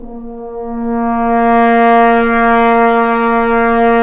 Index of /90_sSampleCDs/Roland L-CD702/VOL-2/BRS_Accent-Swell/BRS_FHns Swells